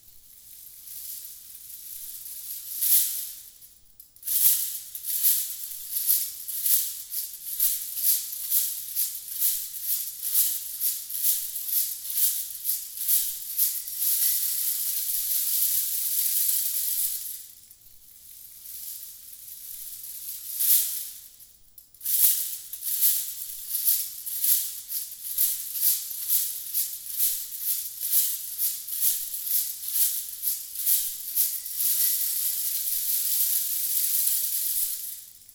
Instrumento musical idiófono golpeado de manera no directa. Su diseño se inspira en varios instrumentos que se asemejan entre sí por su forma de repique sonoro y por la manera de activarse al usarse como el caso del cencerro, la Kaskabeleta, la kaskabiloak y las chapas de pandero.